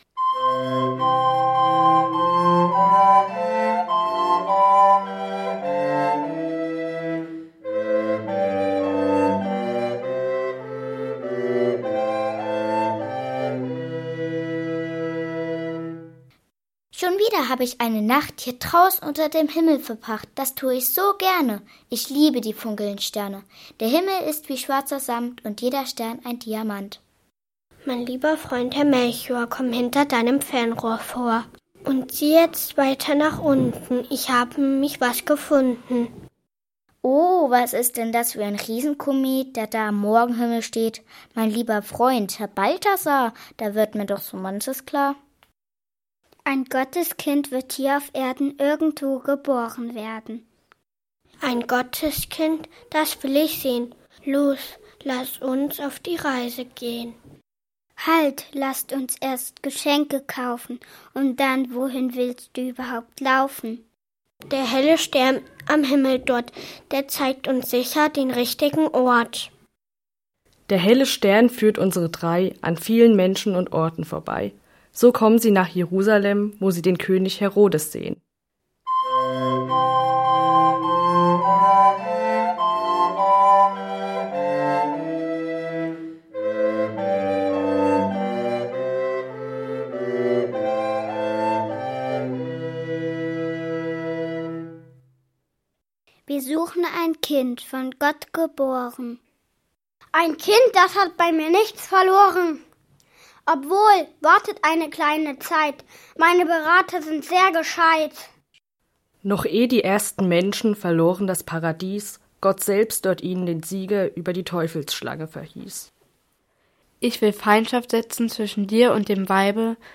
Krippenspiel_2019.mp3